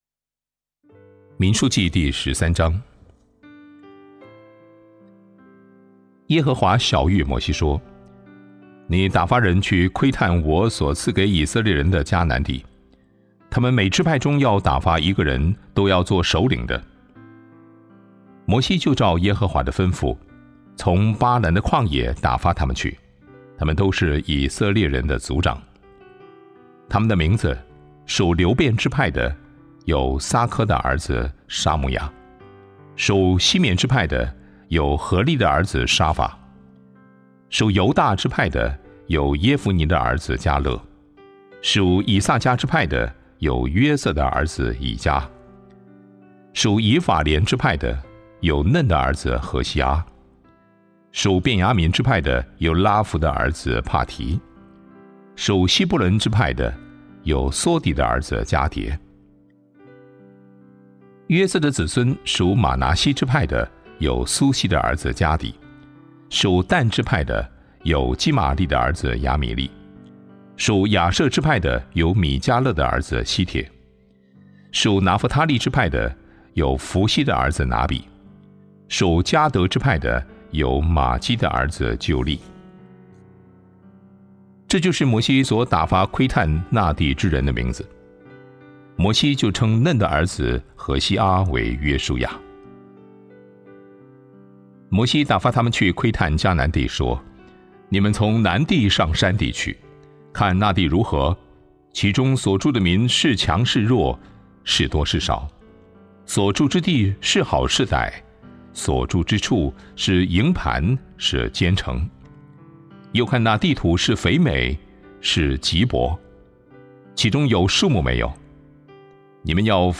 凭着信心，不凭眼见 | Devotional | Thomson Road Baptist Church